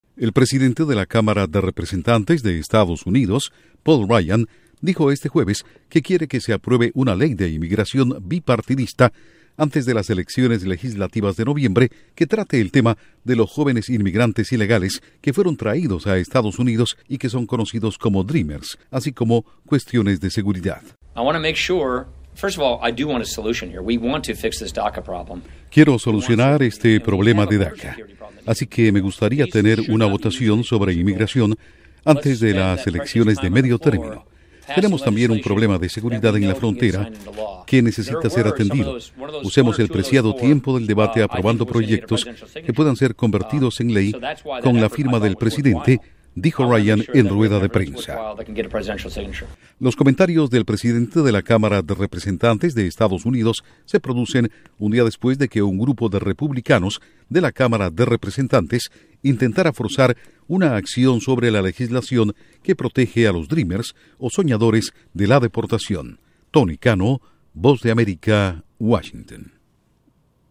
Presidente de la Cámara de Representantes EE.UU. quiere votación sobre "Soñadores" antes de elecciones noviembre. Informa desde la Voz de América en Washington